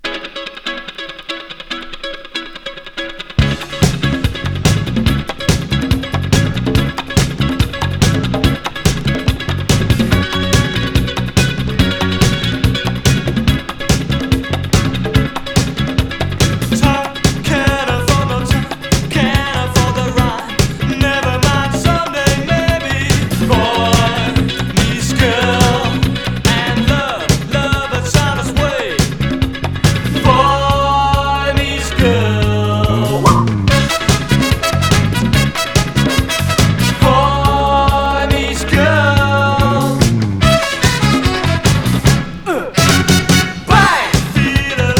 ギターのカッティングとホーンが絡む陽気でポップなサウンド、テンション高めの青春感がなんとも良いのです。
Rock, Pop, New Wave　USA　12inchレコード　33rpm　Stereo